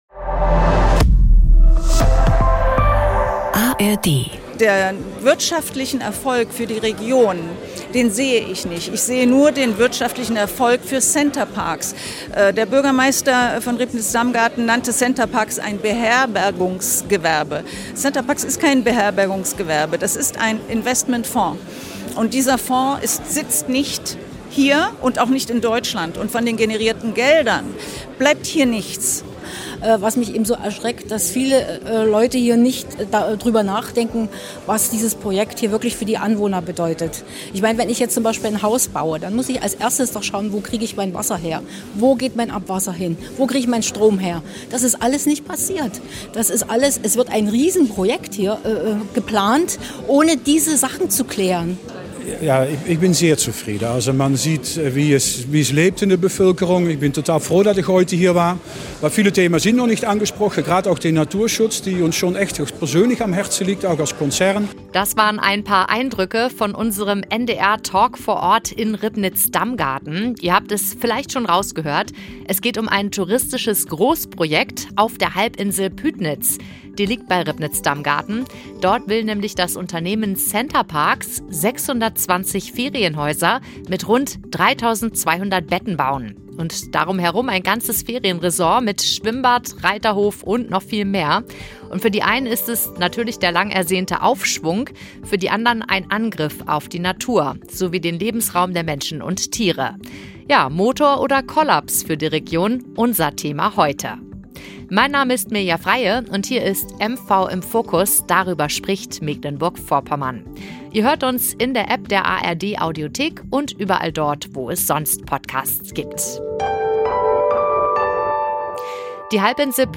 Der geplante Ferienpark in Pütnitz ist hoch umstritten. Befürworter und Kritiker trafen sich beim NDR MV Talk vor Ort. Verkehr, Arbeitskräfte und Abwasser waren nur einige der Streitpunkte. 2029 soll der Ferienpark fertig sein, noch aber gibt es nicht ein...